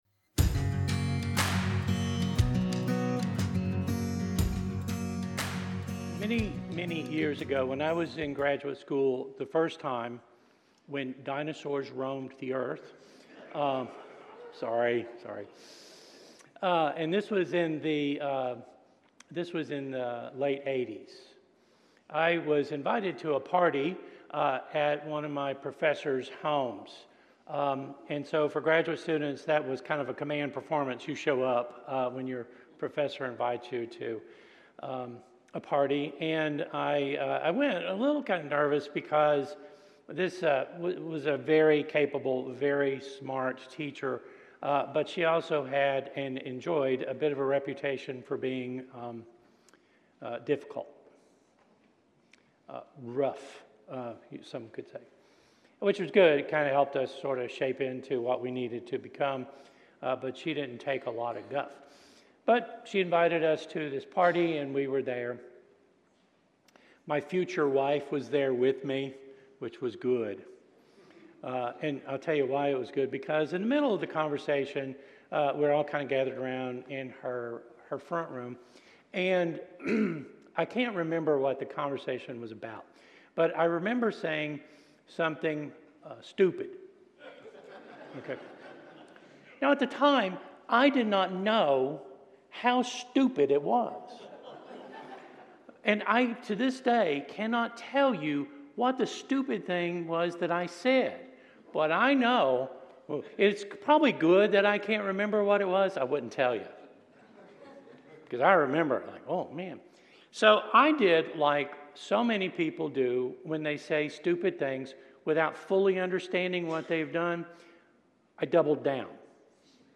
Jacob wrestles with God through the night and becomes a new man - it isn't without a cost, though. In this sermon